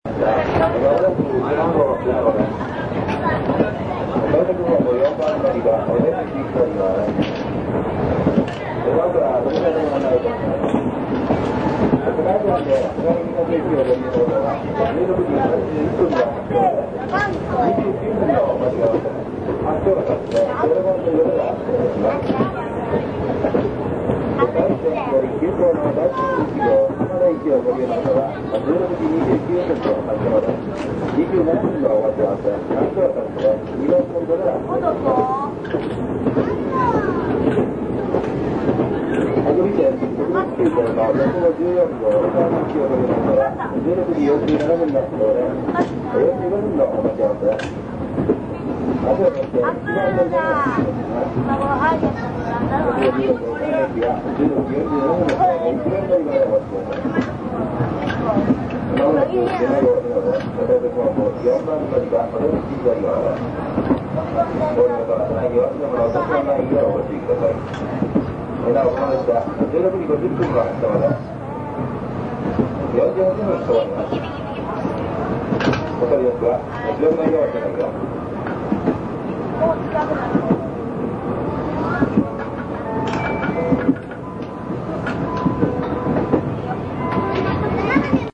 廃止直前の「山陰」号を中心とした山陰地方の列車の音をお楽しみ下さい。
浜田行き普通列車　８３３レ
米子駅到着アナウンス